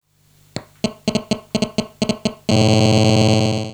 Handy
Das Klangbeispiel ist das Geräusch eines Mobiltelefons, das auf einem eingeschaltenem 100W - Bassverstärker liegt und angerufen wird. Die digitalen Signale werden dann durch den Verstärker als stoßhafte, markante Klänge hörbar.